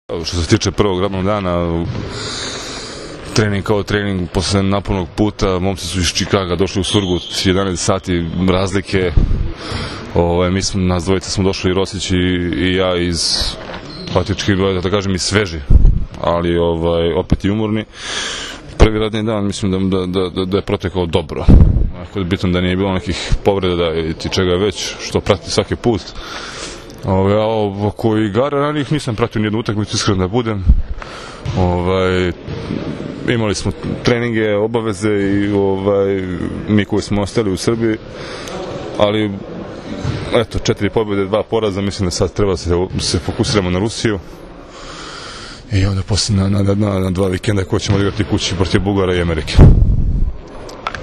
Pogača i so u rukama devojaka obučenih u rusku narodnu nošnju dočekali su, posle dugog puta iz Čikaga, seniore Srbije, uz TV ekipe koje su čekale da intervjuišu reprezentativce Srbije i članove stručnog štaba.
IZJAVA VLADE PETKOVIĆA